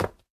tbd-station-14/Resources/Audio/Effects/Footsteps/wood4.ogg at d1661c1bf7f75c2a0759c08ed6b901b7b6f3388c
wood4.ogg